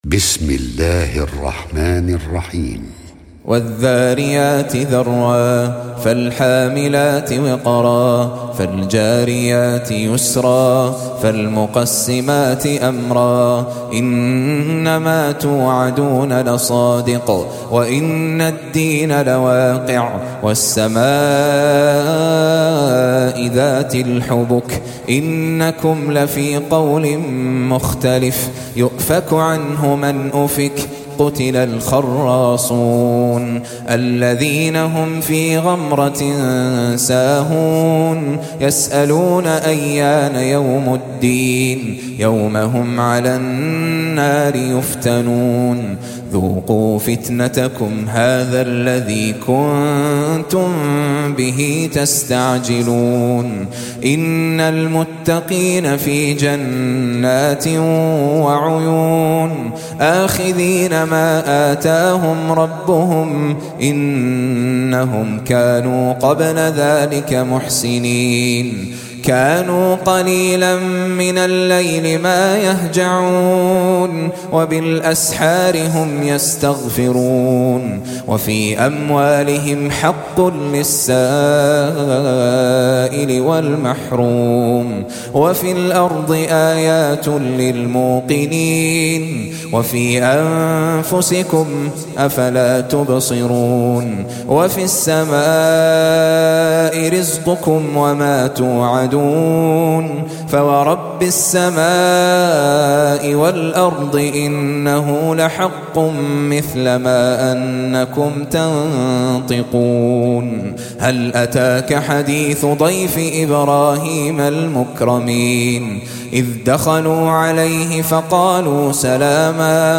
Surah Sequence تتابع السورة Download Surah حمّل السورة Reciting Murattalah Audio for 51. Surah Az-Z�riy�t سورة الذاريات N.B *Surah Includes Al-Basmalah Reciters Sequents تتابع التلاوات Reciters Repeats تكرار التلاوات